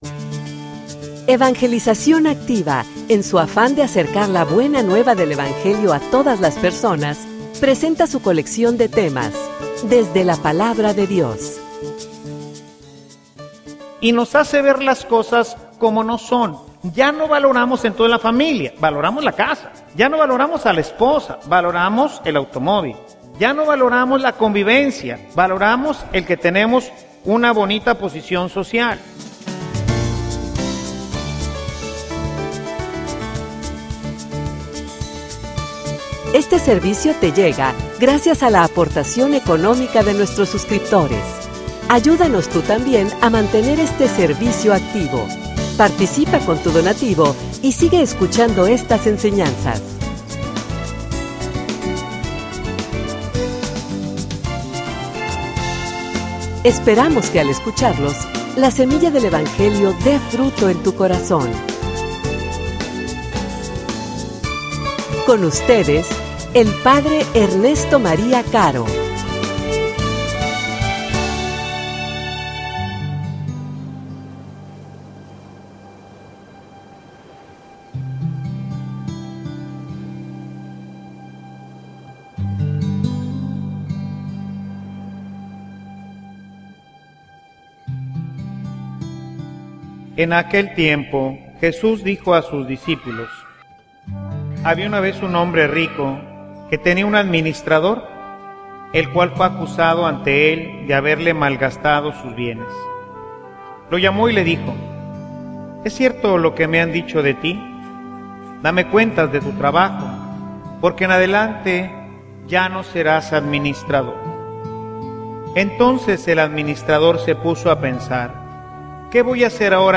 homilia_La_santa_indiferencia.mp3